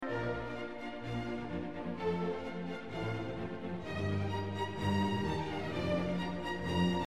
Well I failed miserably at attempting (a), but now I hear it it sounds like Mozart.